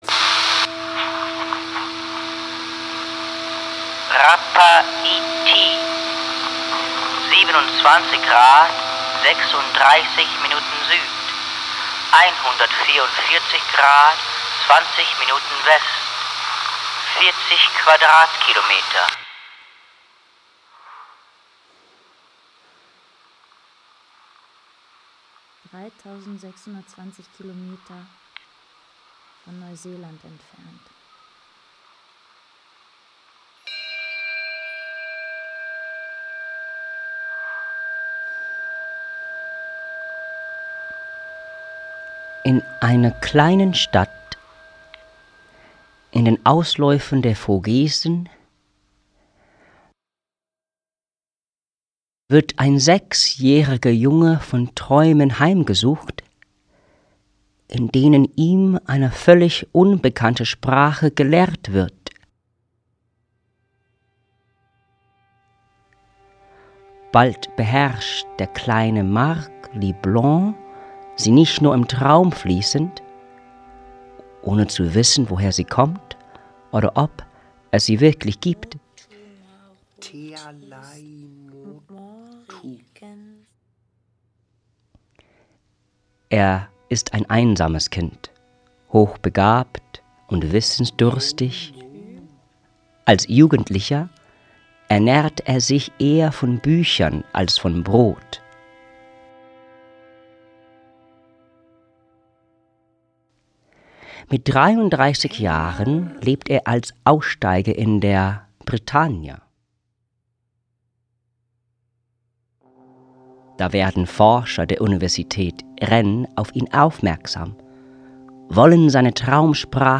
Ein musikalisches Hörspiel